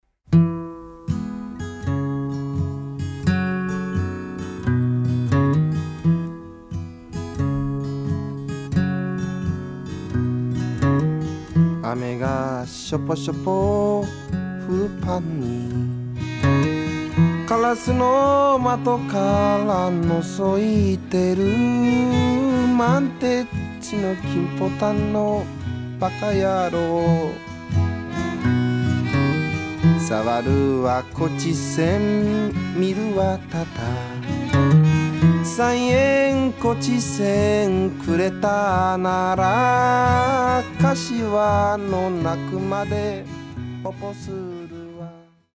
ジャンル：フォーク